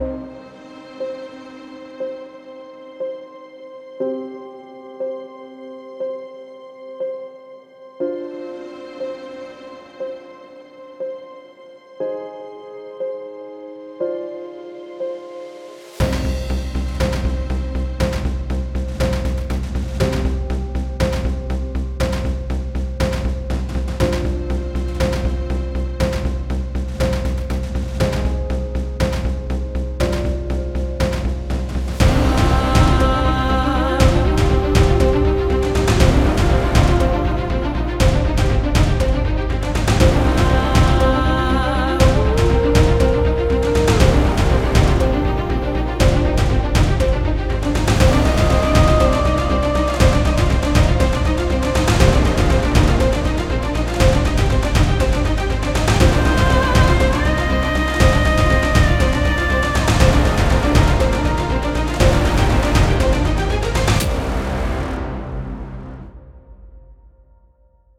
立即为音乐，电影和视频游戏制作人获得史诗般的现代电影鼓。
期待新的高能量，强大的现代电影鼓声循环，史诗般的打击，迷人的打击乐，令人叹为观止的鼓声等等-涵盖您从幻想到冒险，从动作到科幻等任何项目的所有需求，恐怖惊悚片，战争惊悚片
• 205 Ensemble Loops
• 43 Percussion Loops
• 134 Layered Hits
• 54 Whooshes
• 16 Timpanis
• 15 Big Drums